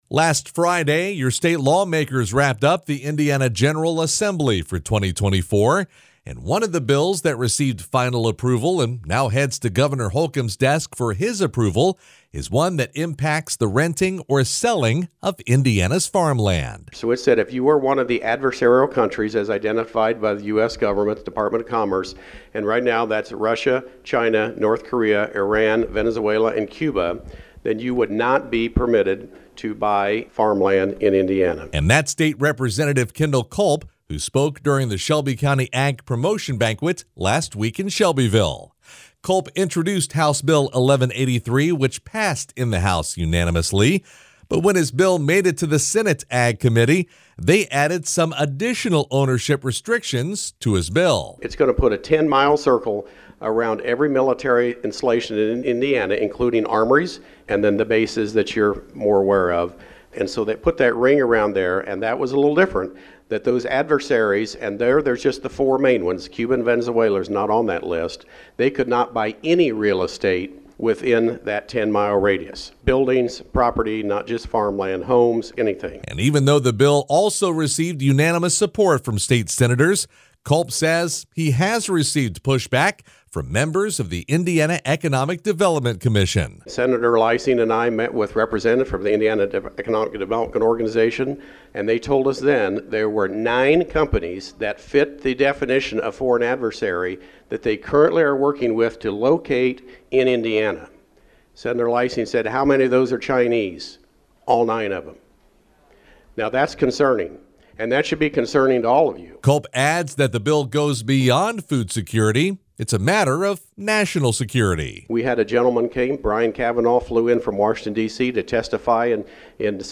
State Rep. Kendell Culp (R-16th District) speaks before the “Taste of Shelby County Ag Promotion” banquet on March 6, 2024 at Horseshoe Racing & Casino in Shelbyville, Indiana.